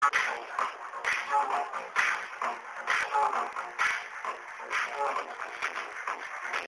to let you guys have more info, its kind of a house music..